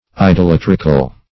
Idolatrical \I`do*lat"ric*al\, a.